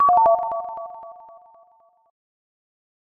Instant Sound Buttons
0255_Share_'Weird_route_jingle'.mp3